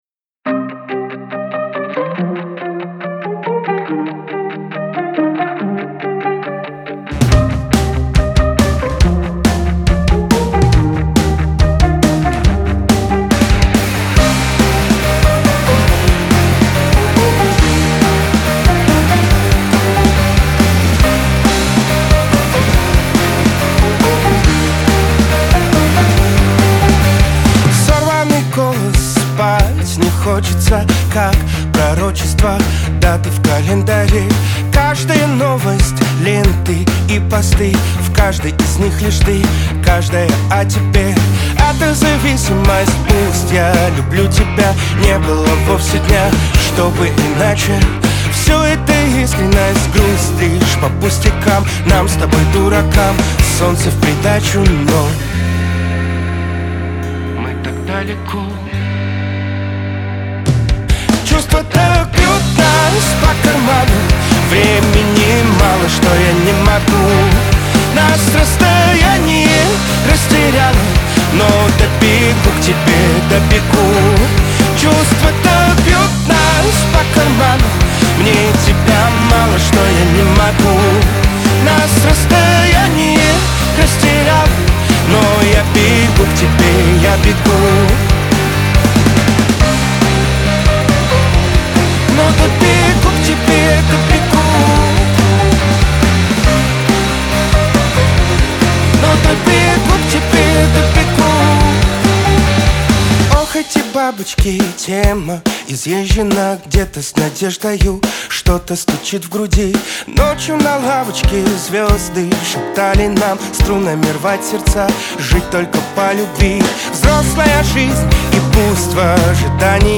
дуэт
эстрада , pop
ХАУС-РЭП